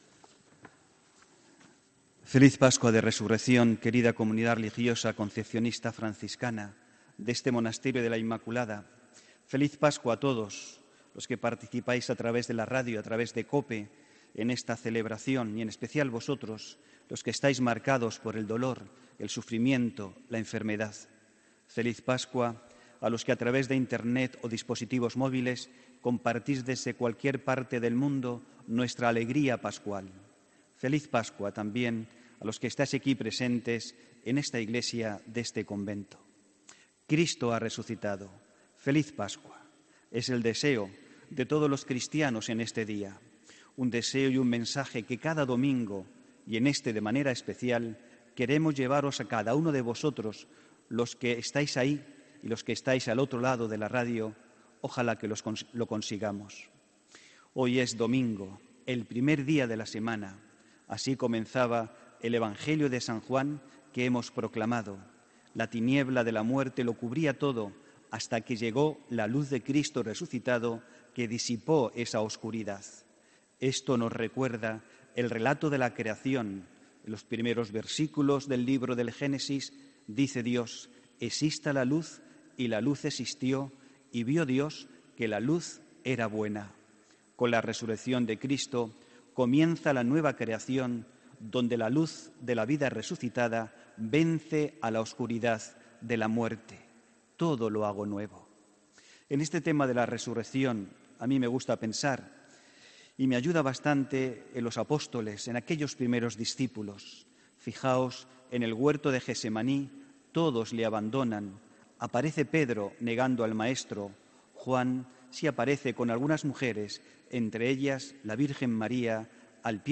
HOMILÍA 1 ABRIL 2018